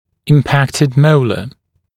[ɪm’pæktɪd ‘məulə][им’пэктид ‘моулэ]ретинированный моляр